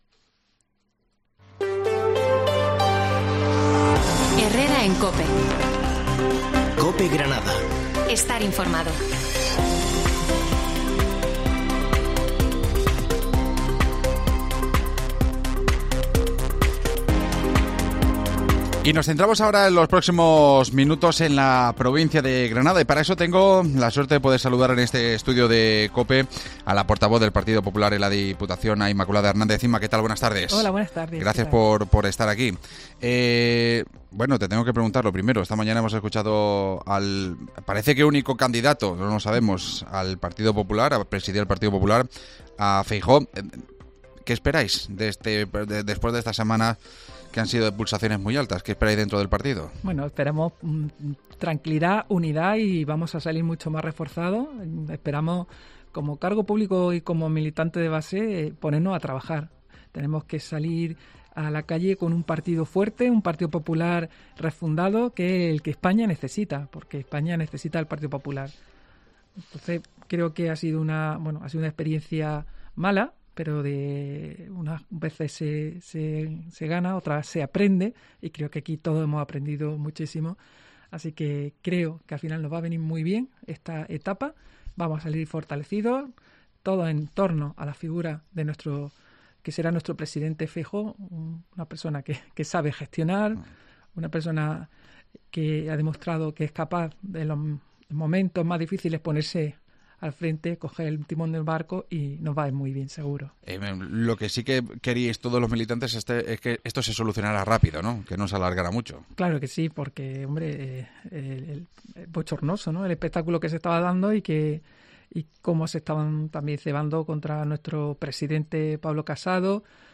AUDIO: La portavoz del PP en la diputación granadina, Inma Hernández, repasa la actualidad política de la provincia